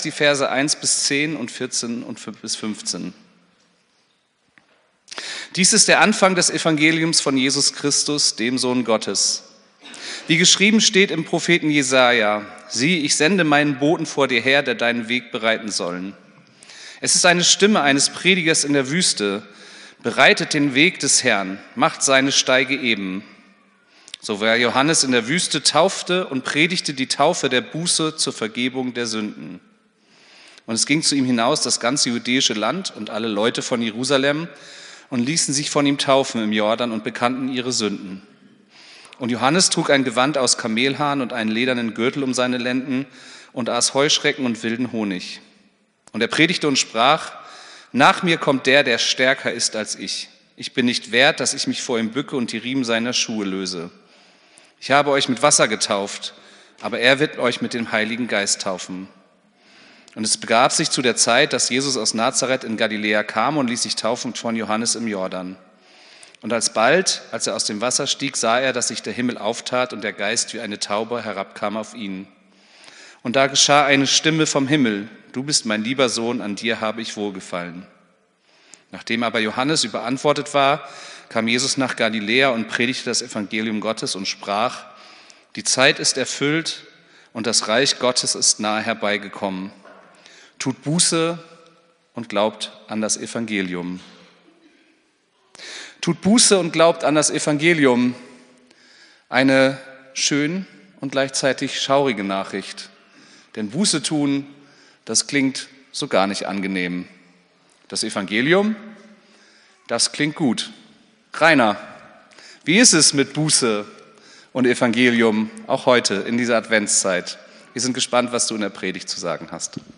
Predigt vom 15.12.2024